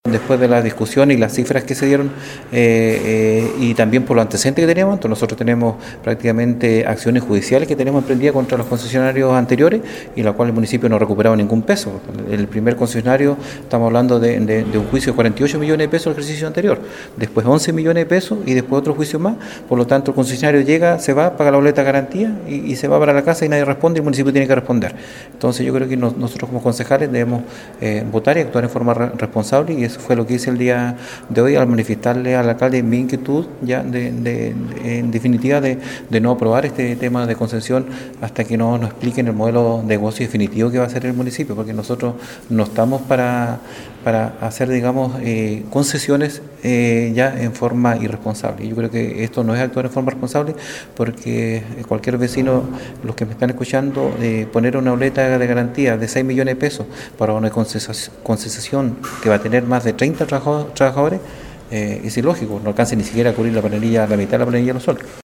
03-CONCEJAL-ALEX-MUÑOZ-2.mp3